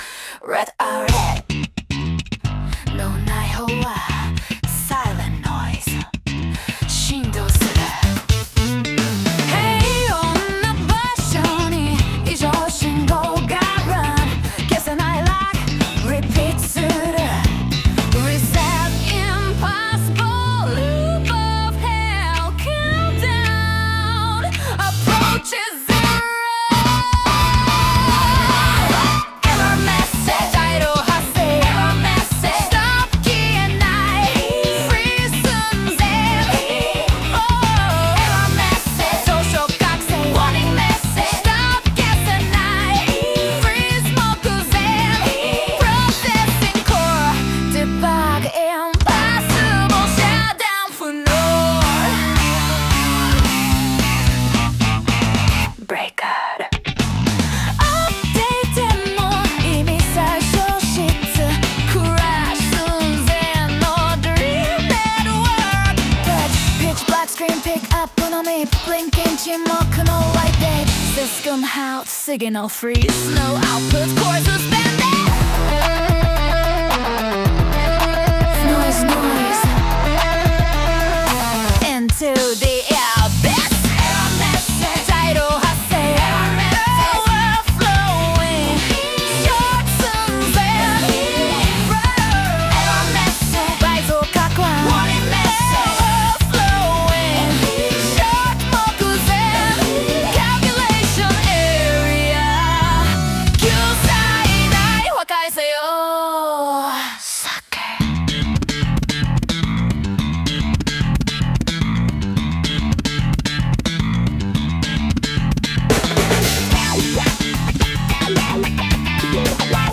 女性ボーカル
イメージ：ファンク・ロック,チョッパーベース,スラップベース,ミッドテンポグルーヴ